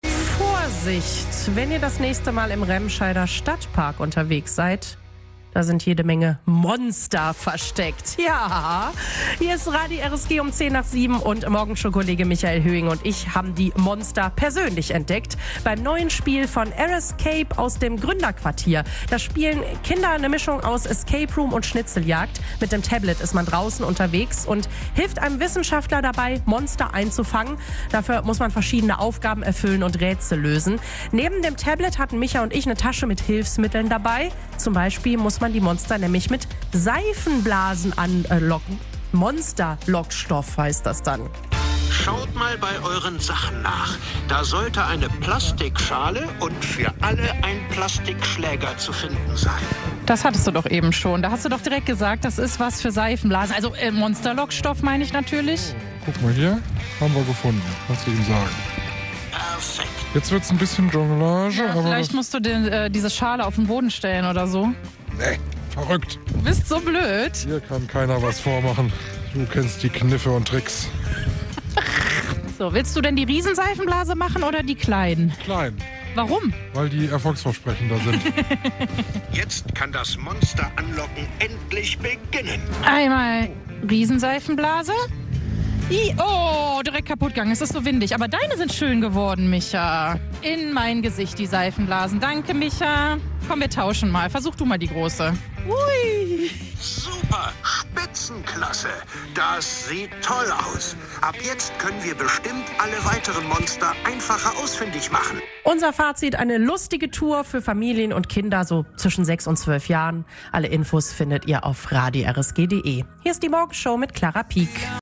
Eindrücke von der Monsterforscher-Tour